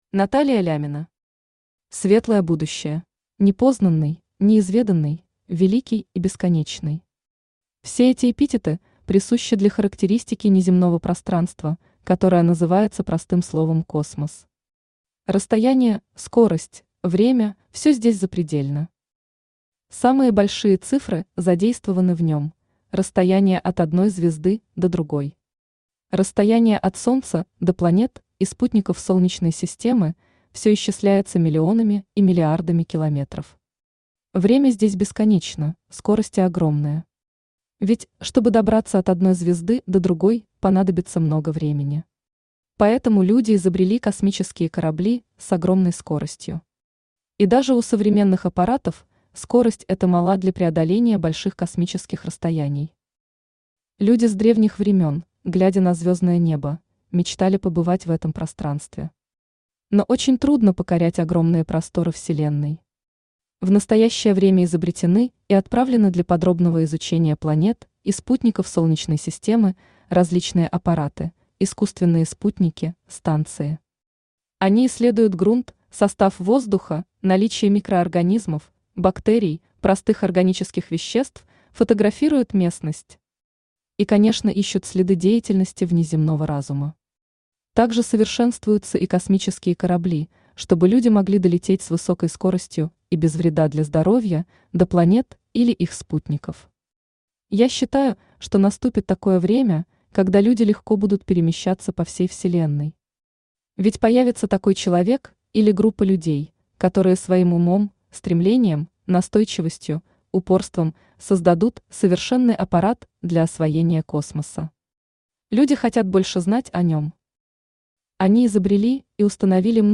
Aудиокнига Светлое будущее Автор Наталия Леонидовна Лямина Читает аудиокнигу Авточтец ЛитРес.